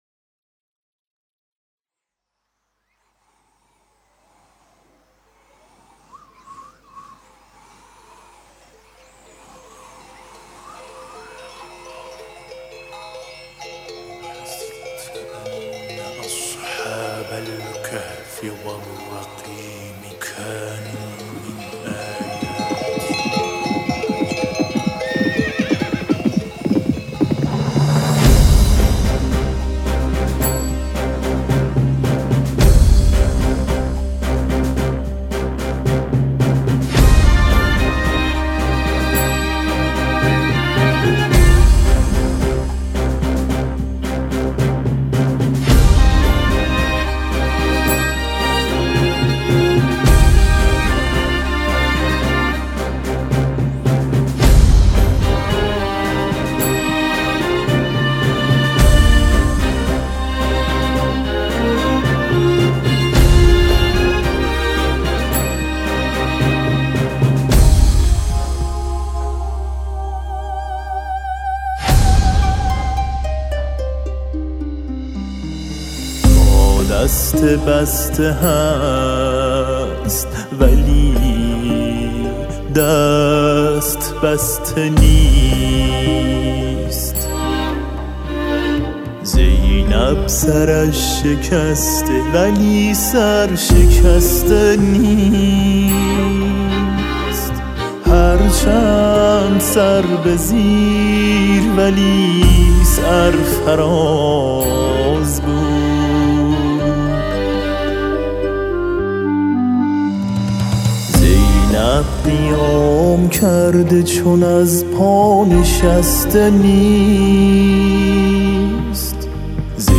ویلون و ویلون آلتو
سه تار
پیانو و سازهای الکترونیک